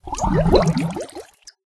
bubbles.ogg